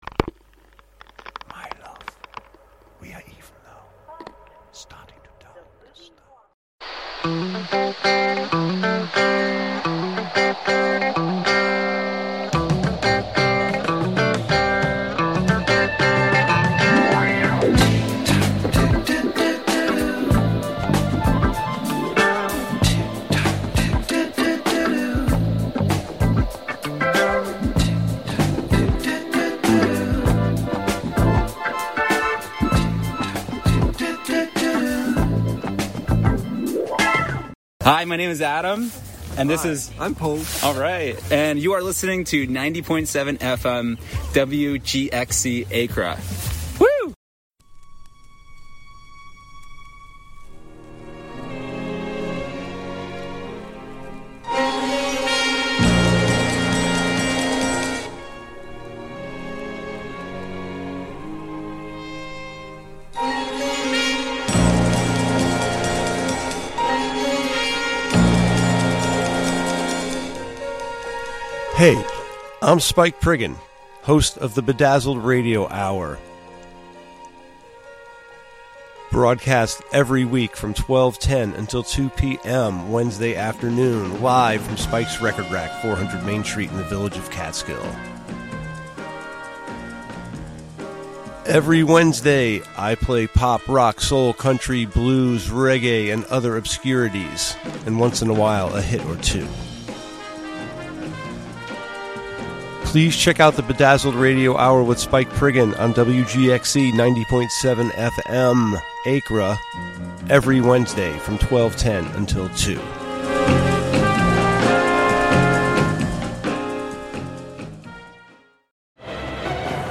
Catskill studio